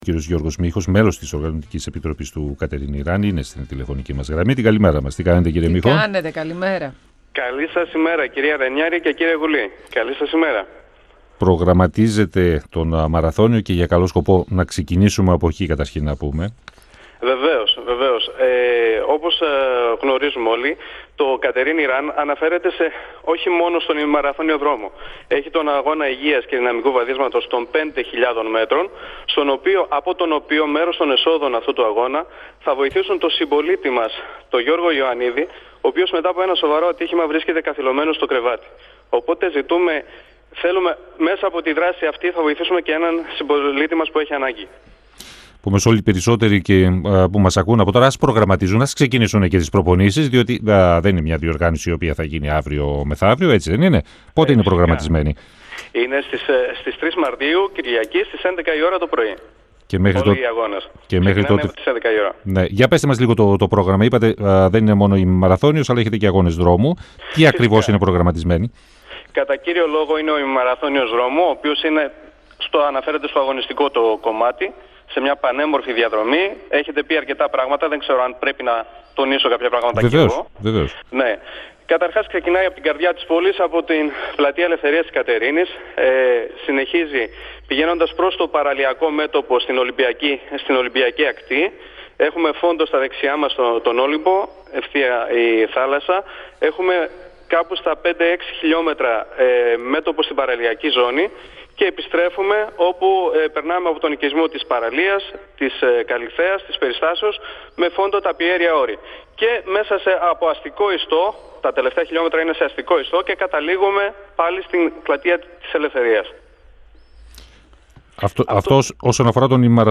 στον 102FM του Ρ.Σ.Μ. της ΕΡΤ3